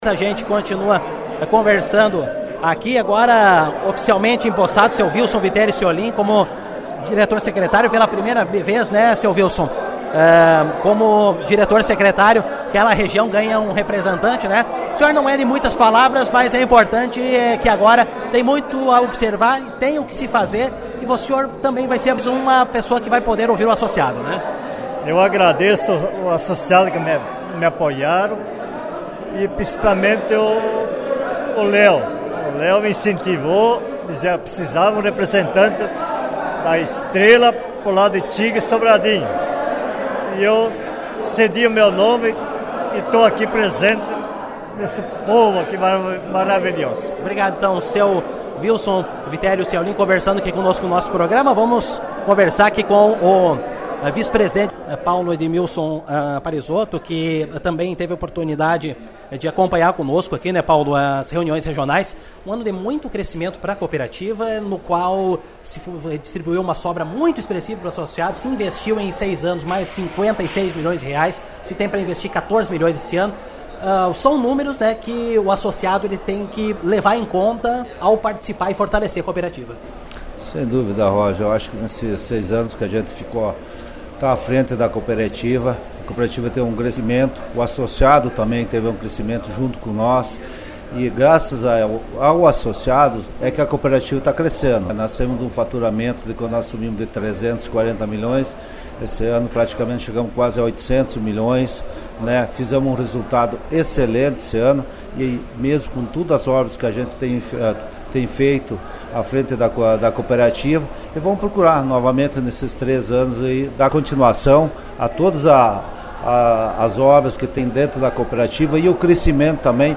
Ouça aqui a entrevista da diretoria eleita.